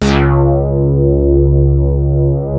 P.5 C#3 1.wav